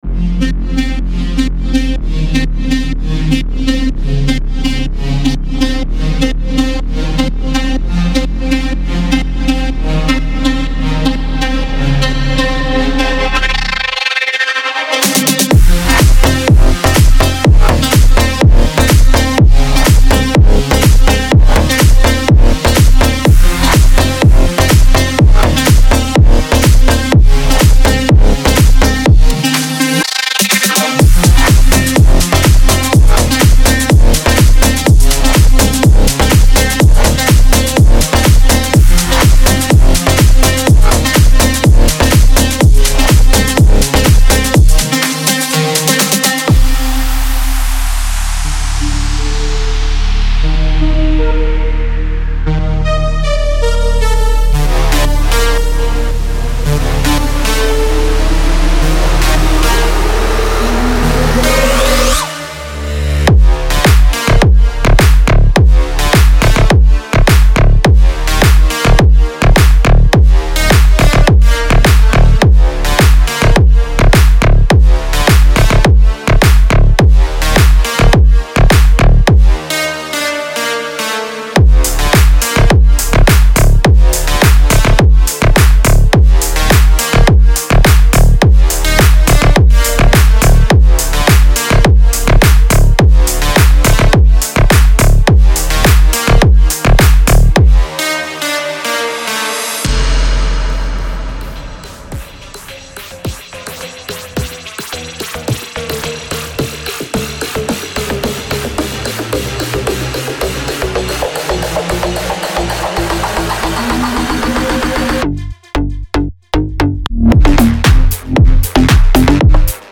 2026-01-01 Slap House 106 推广
精选的 Trap 底鼓、清脆的军鼓、充满活力的拍手声、悦耳的踩镲等等，应有尽有。
这些贝斯元素旨在成为您音乐作品的骨架，为任何一首Slap House金曲提供必要的能量。
这些采样提供了过渡、渐强以及令人着迷的合成器旋律等必备元素，让您的听众欲罢不能。
旋律循环：这些惊艳的琶音、主音和和弦循环将释放您音乐作品的旋律潜力。